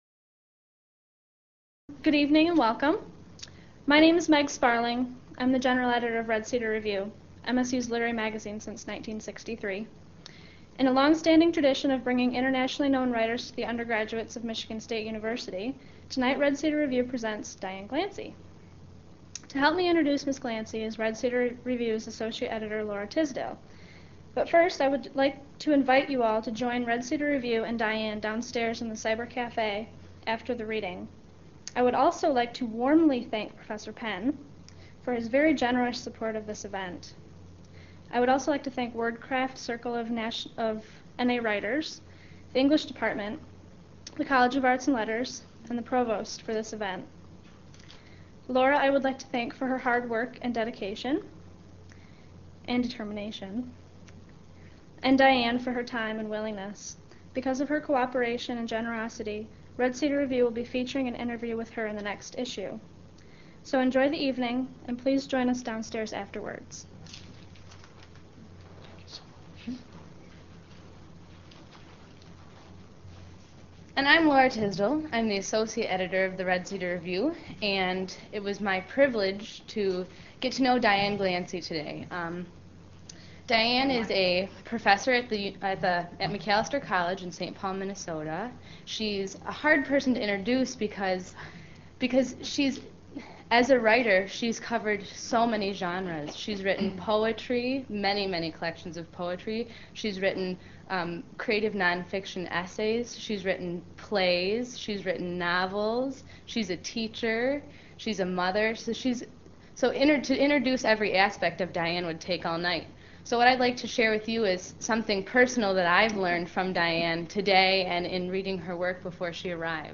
Poet and Novelist Diane Glancy reads her selected works at the Michigan Writers Series